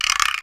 Index of /kb6/Akai_XR-10/Percussion
Guiro-01.wav